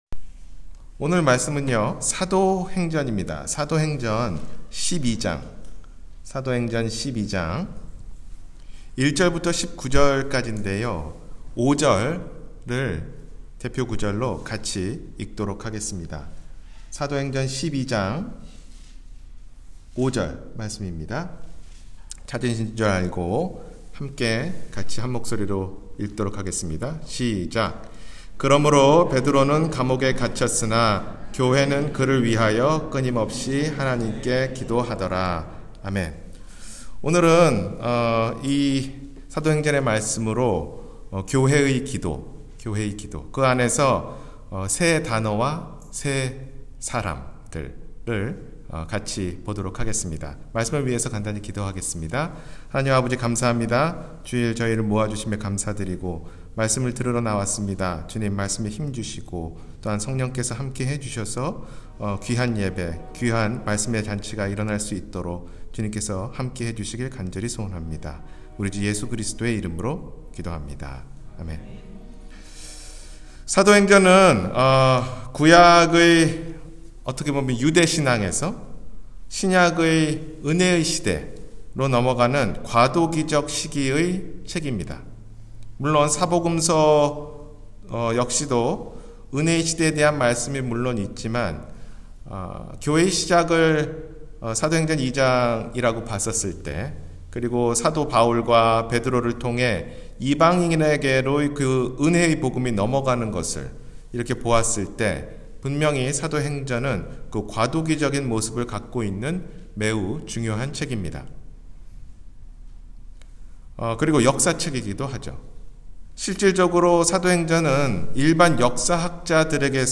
교회의 기도 (세단어와 세사람)- 주일설교